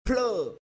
Plug